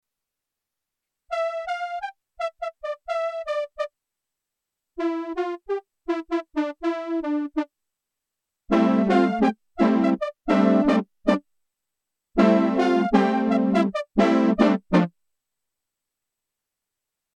Sauf mention explicite, les enregistrements sont faits sans aucun effet.
p.57 – Sledge : pêches de cuivre avec effet d’embouchure sur l’attaque
SLEDGE brass synth
SLEDGE-brass-synth.mp3